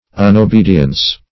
Unobedience \Un`o*be"di*ence\, n.